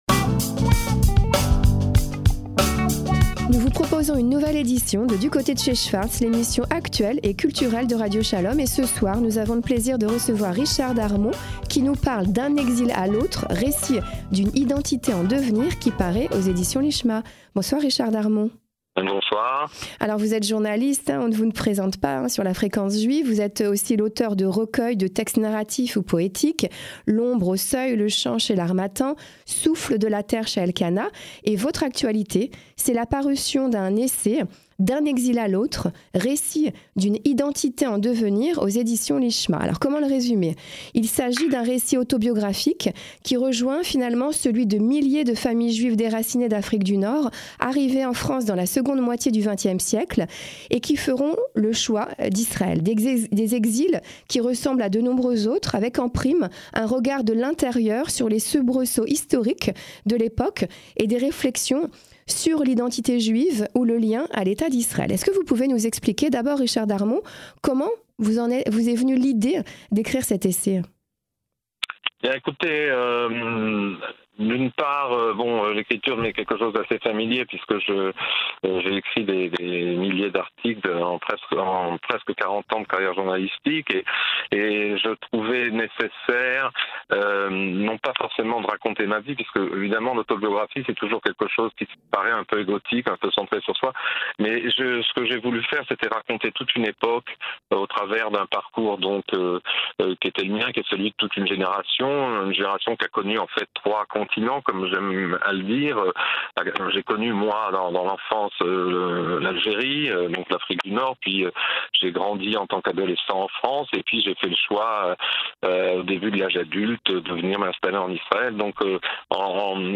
en direct d’Israël